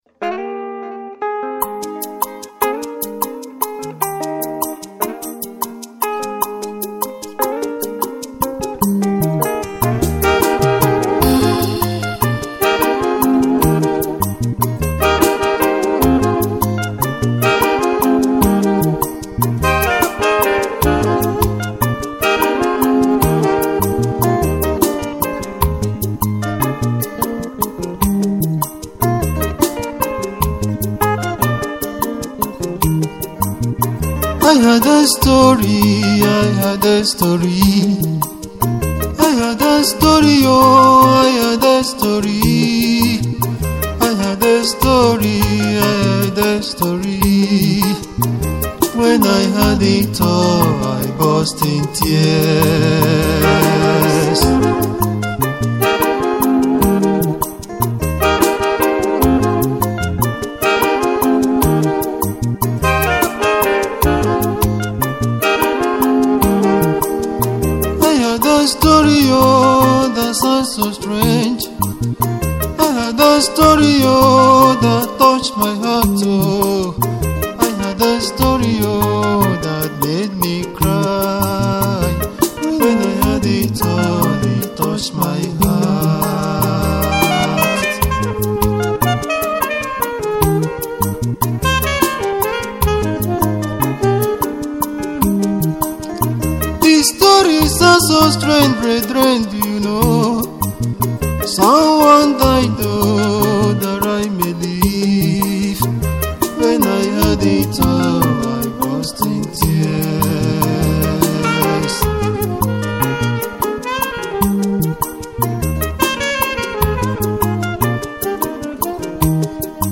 highlife song